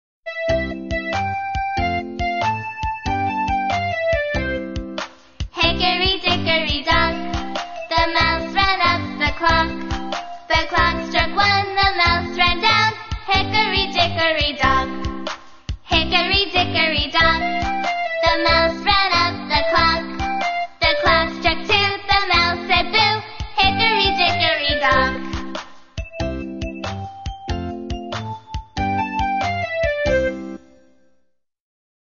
在线英语听力室英语儿歌274首 第67期:Hickory dickory dock的听力文件下载,收录了274首发音地道纯正，音乐节奏活泼动人的英文儿歌，从小培养对英语的爱好，为以后萌娃学习更多的英语知识，打下坚实的基础。